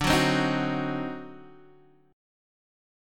D7b9 chord